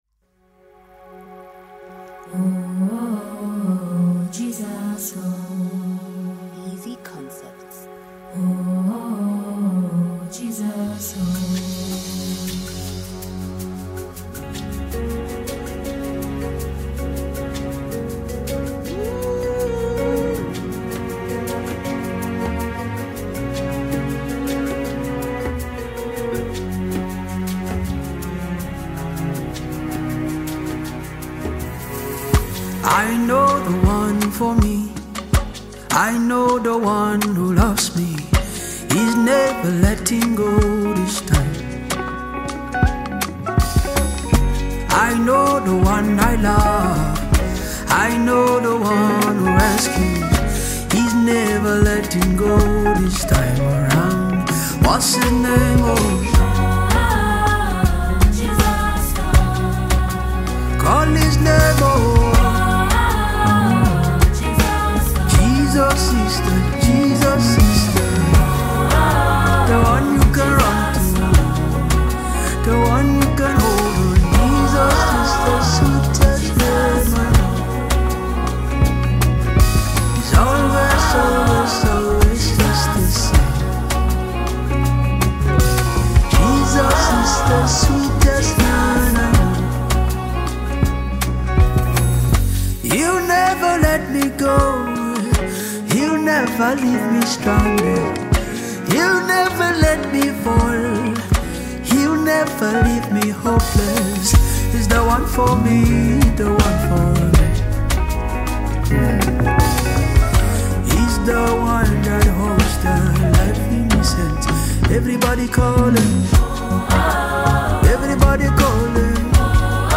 Home » Gospel